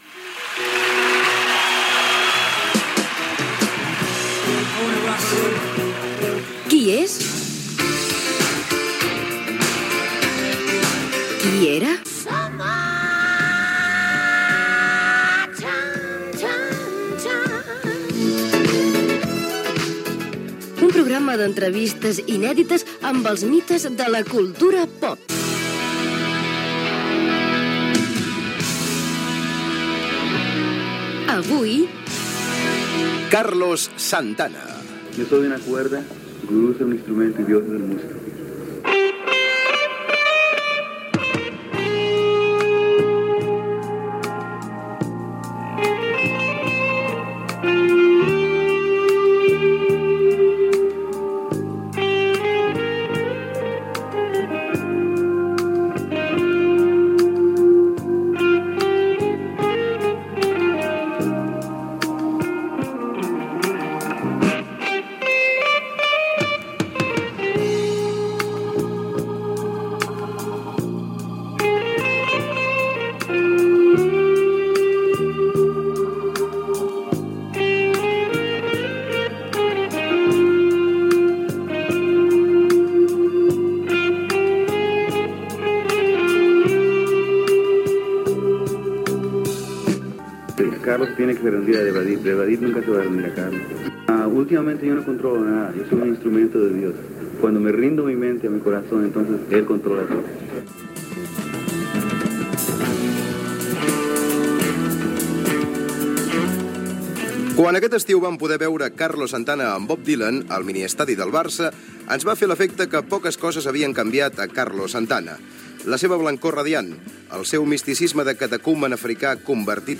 Careta del programa, espai dedicat al músic Carlos Santana amb fragments de delcaracions seves quan va actuar a l'estiu del 1984 a Barcelona
Musical